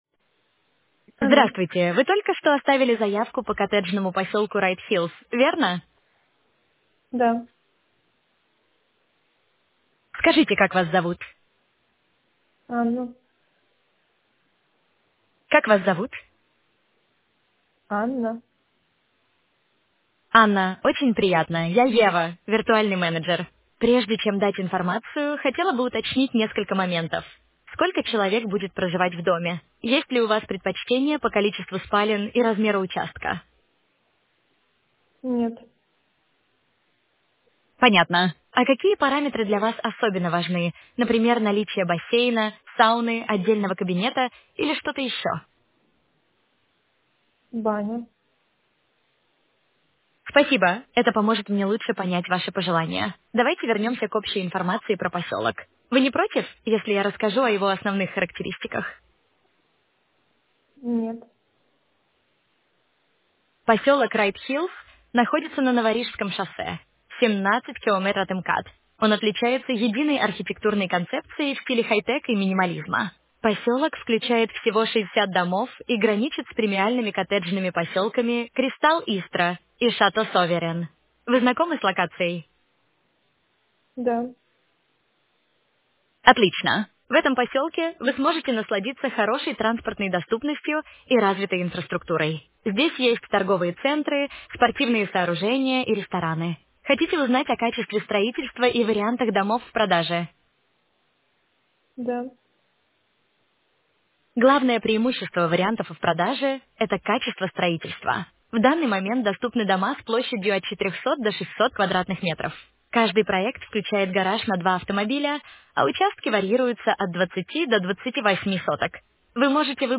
Используем самые современные языковые модели и технологии благодаря которым агенты общаются как "живые люди"
Консультация AI-Ассистента для B2С
• Обладают приятным «живым» голосом